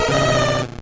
Cri de Teddiursa dans Pokémon Or et Argent.